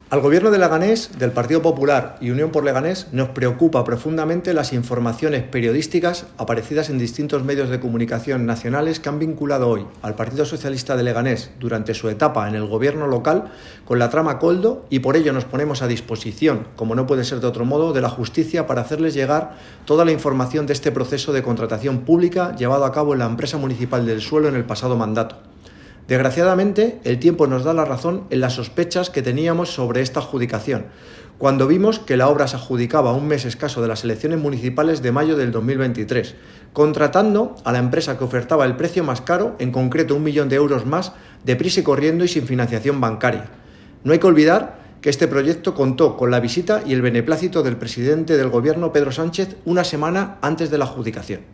ALCALDE-RECUENCO-VIVIENDAS-KOLDO.mp3